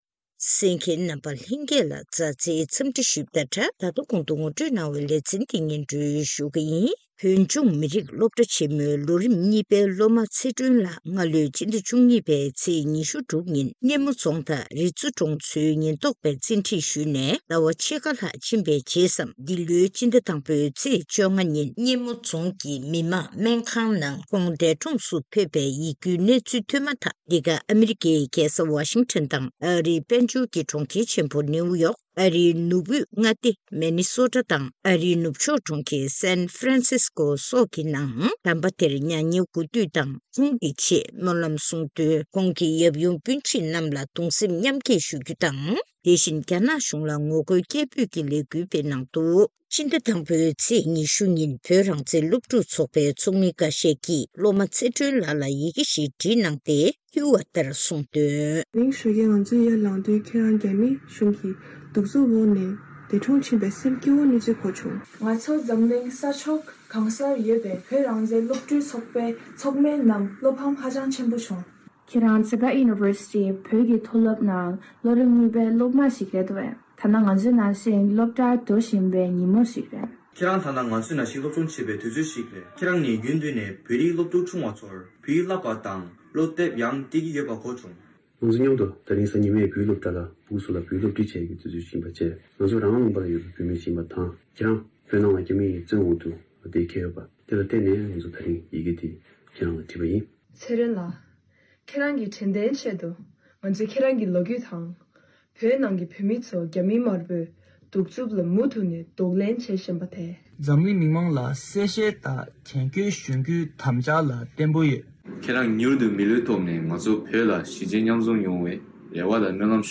ཐེངས་འདིའི་གནས་འདྲིའི་ལེ་ཚན་ནང་།